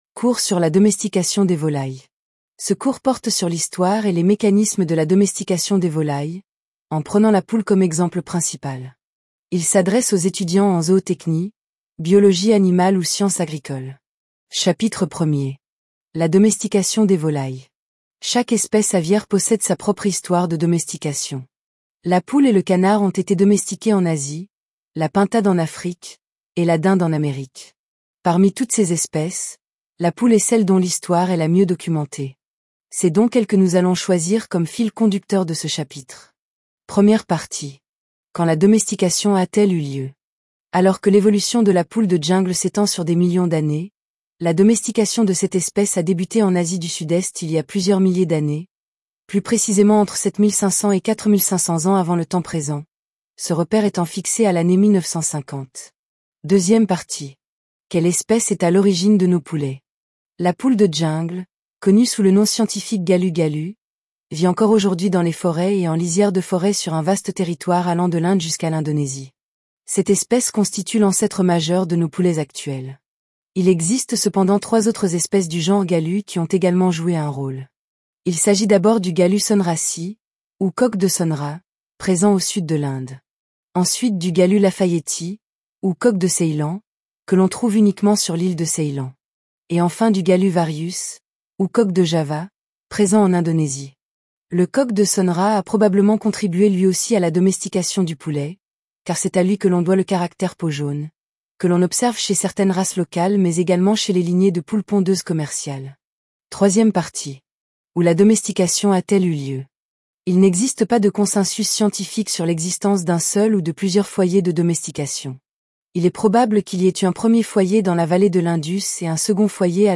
La domestication des volailles - Lecture Audio (MP3): La domestication des volailles - Lecture Audio (MP3) | APES-Tchad
Podcast : Domestication Des Volailles Tts
DOMESTICATION_DES_VOLAILLES_TTS.mp3